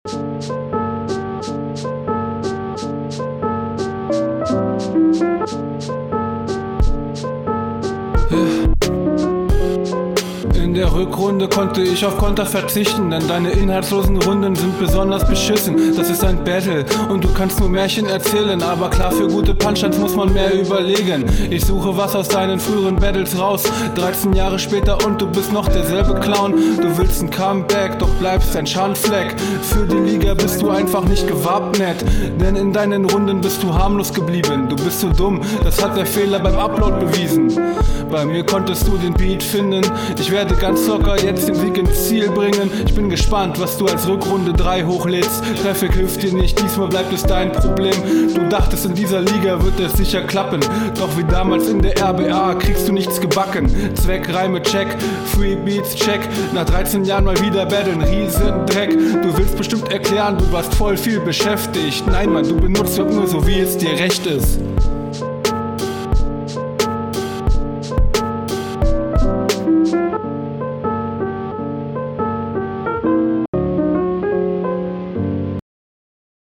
Audio dieses Mal besser aber immer noch sehr viel Hall auf der Stimme.
Dein Lispeln fällt hier sehr auf.